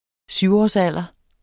Udtale [ ˈsywɒs- ]